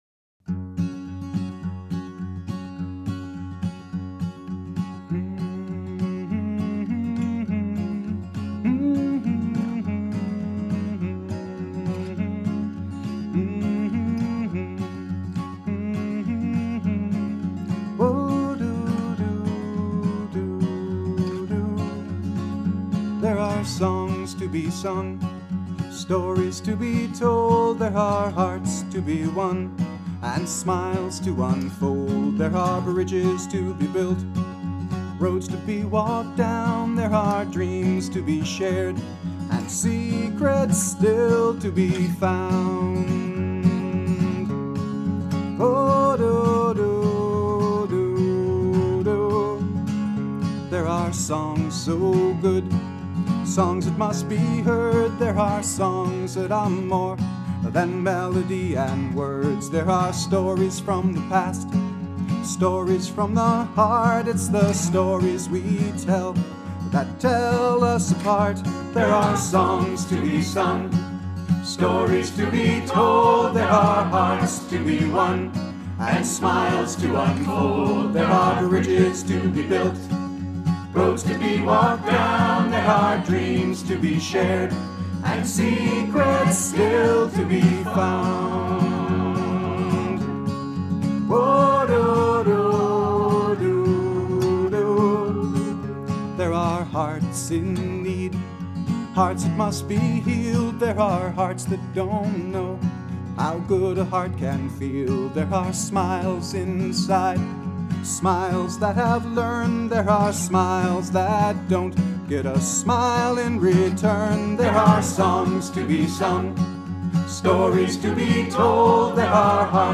guitar & vocals
01-there-are-songs-to-be-sung.mp3